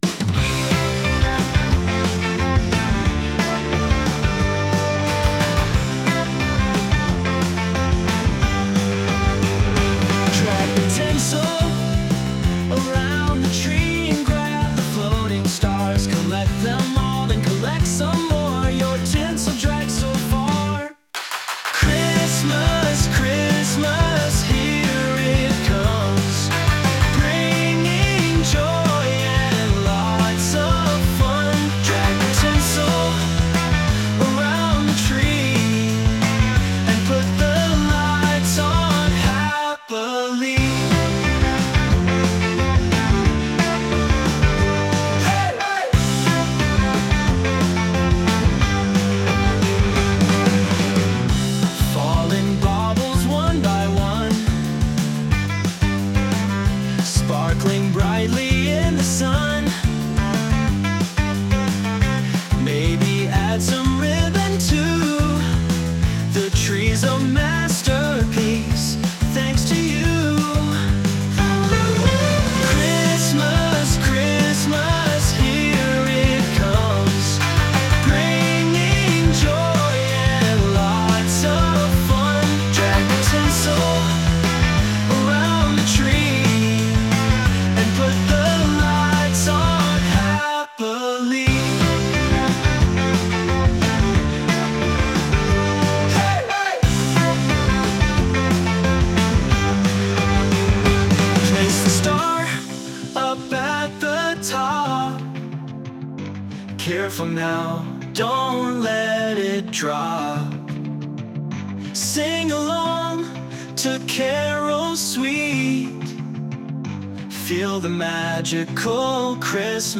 Sung by Suno
Pet_Rock_(inst)_(Cover)_mp3.mp3